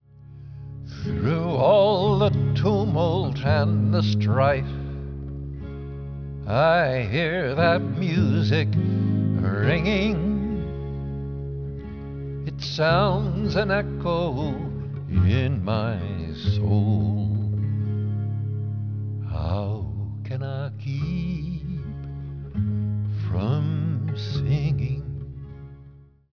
Quaker hymn from North Carolina
12-string guitar